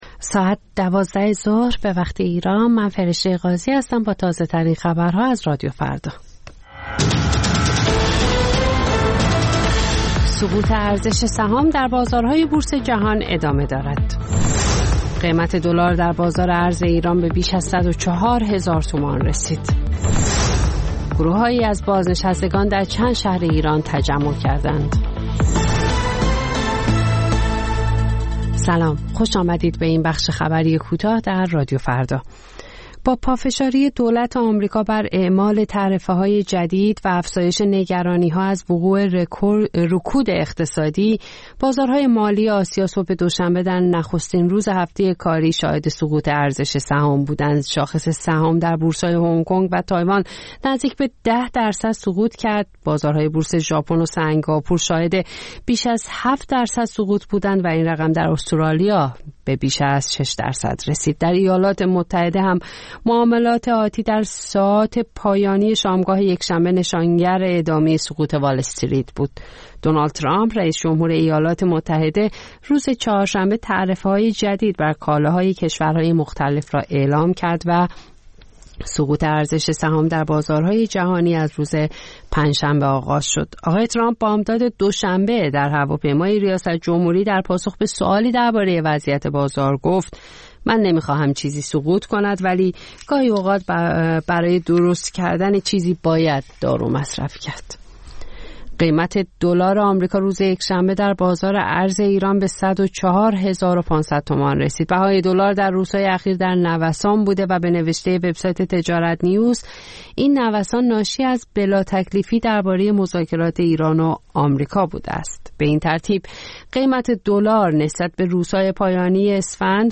سرخط خبرها ۱۲:۰۰